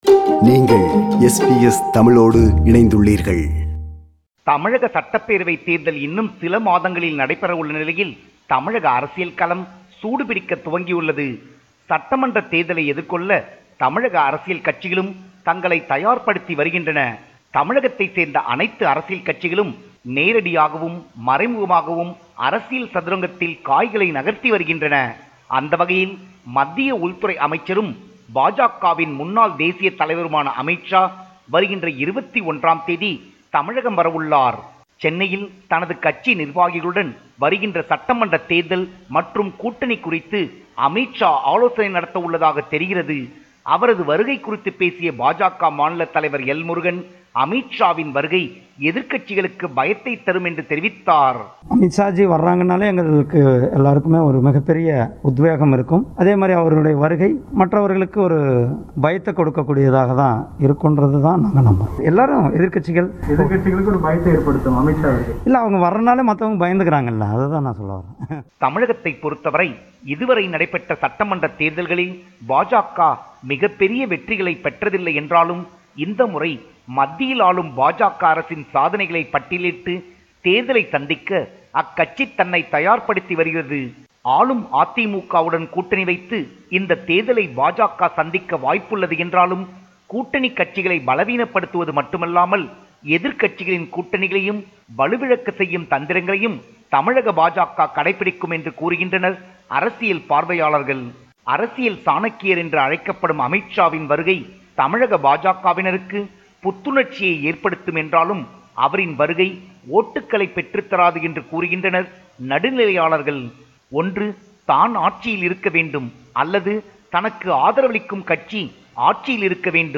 compiled a report focusing on major events/news in Tamil Nadu